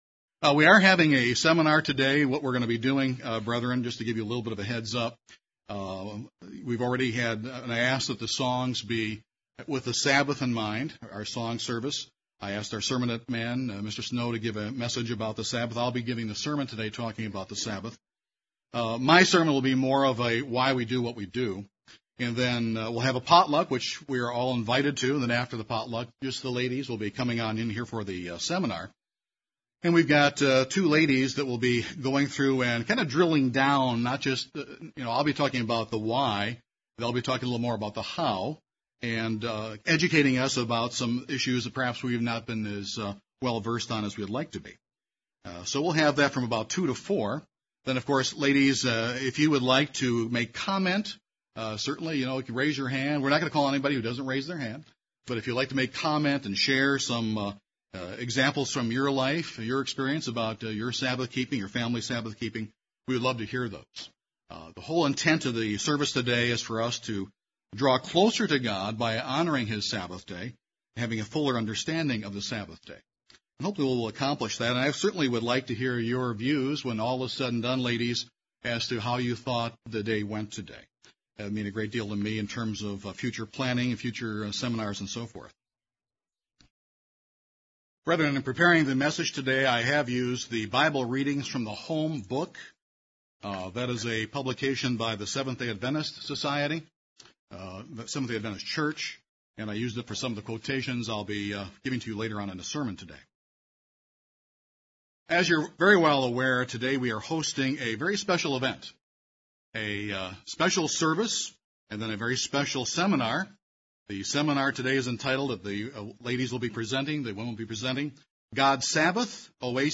A message on drawing closer to God by honoring His Sabbath day and having a fuller understanding of this day.